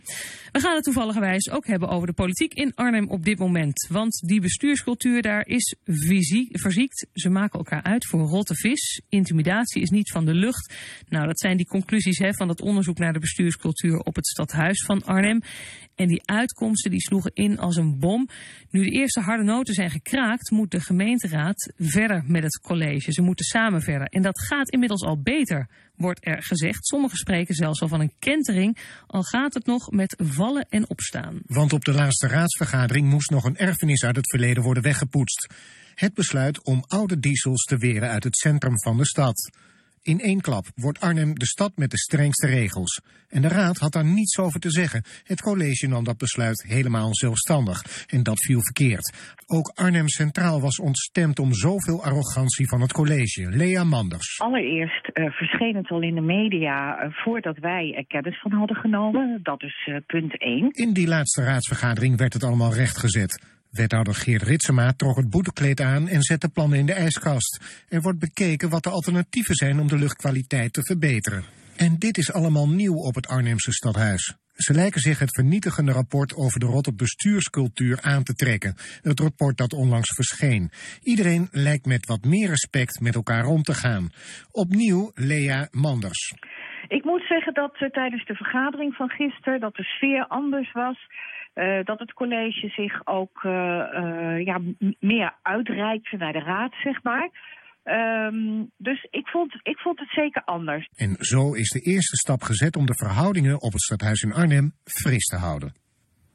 Op woensdag 8 november jl.. was fractievoorzitter Lea Manders met Omroep Gelderland in gesprek over de bestuurscultuur in de gemeenteraad van Arnhem.